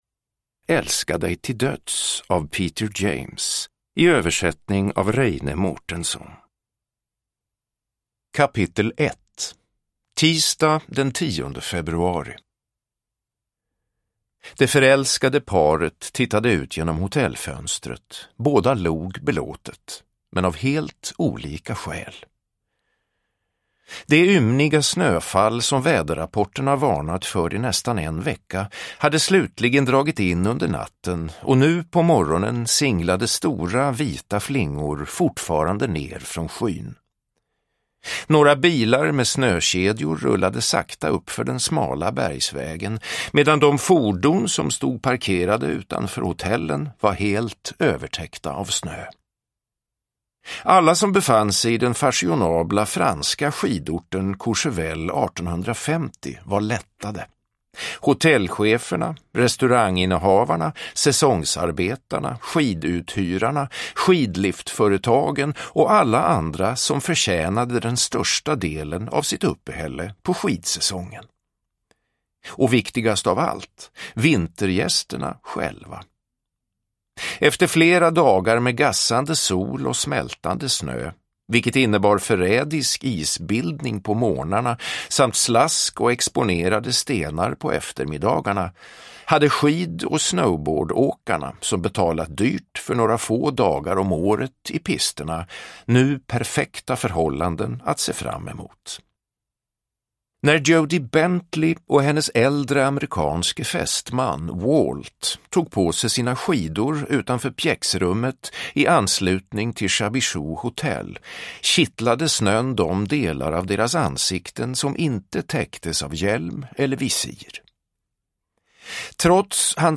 Produkttyp: Digitala böcker
Uppläsare: Peder Falk